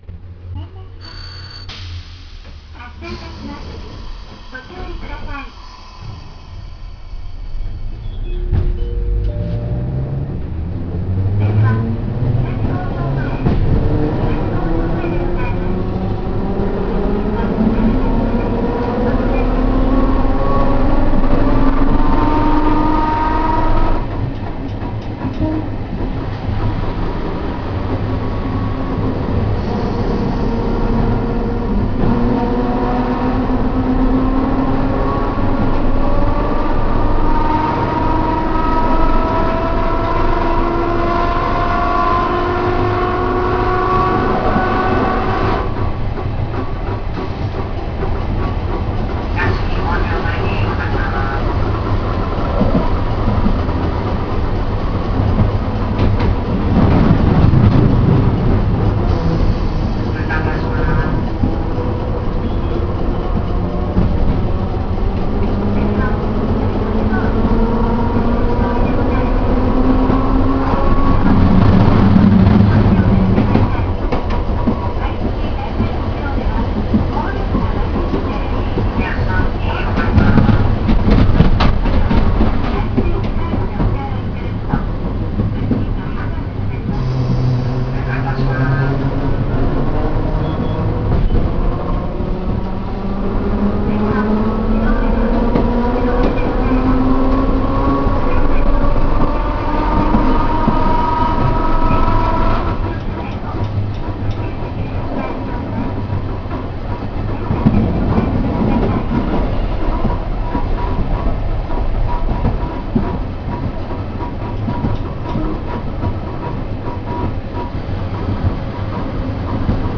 ・600形走行音
【後免線】後免西町→小篭通（途中駅通過）（3分37秒：1.15MB）
当然ながら吊り掛け式です。ドアブザーの頼りなさもまた良きかな。